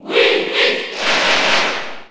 Category:Crowd cheers (SSB4) You cannot overwrite this file.
Wii_Fit_Trainer_Cheer_French_NTSC_SSB4.ogg